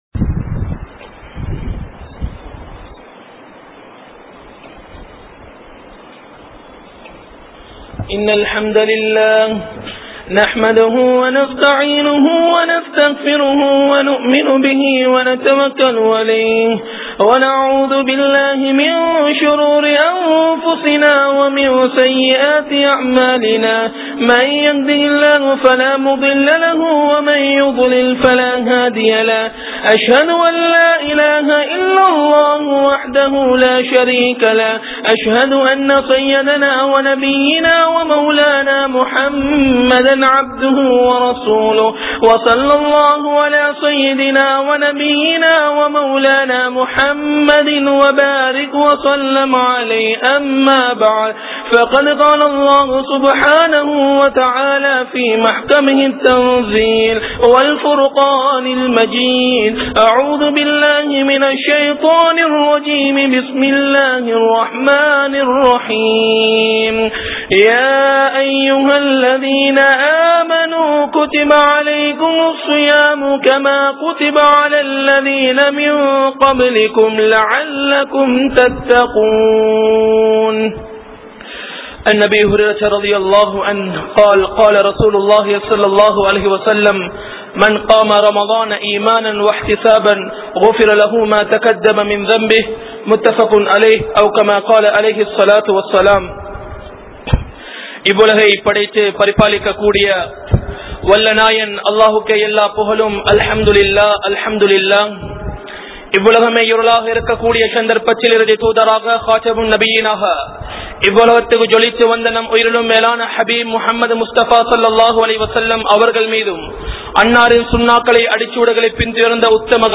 Ramalaan | Audio Bayans | All Ceylon Muslim Youth Community | Addalaichenai